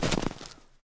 snow_break.ogg